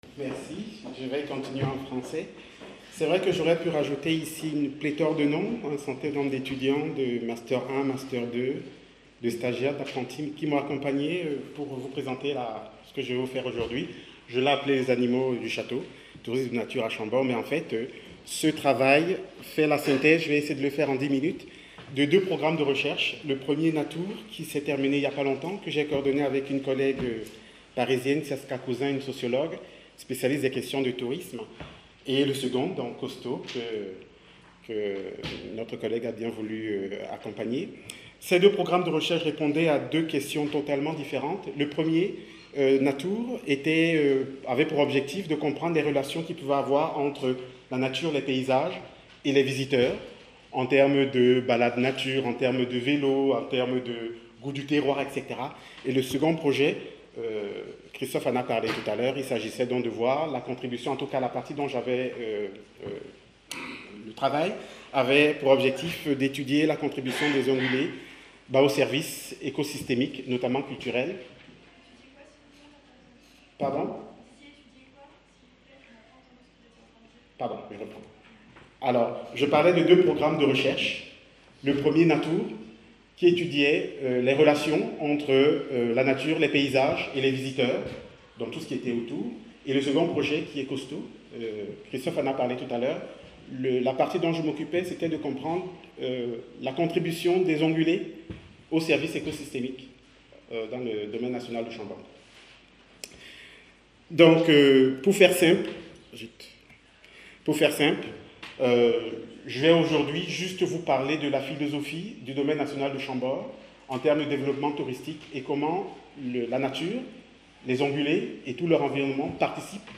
Ce colloque international, qui s'est tenu au sein du Domaine national de Chambord (26 et 27 mars 2019), s'inscrit dans le cadre du projet de recherche COSTAUD (Contribution des OnguléS au foncTionnement de l’écosystème et AUx services rendus à ChamborD, financé par la Région Centre-Val de Loire et porté par l'Irstea, 2016-2019).